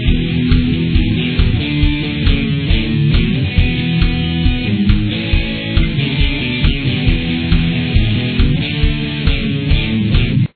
Pre-Chorus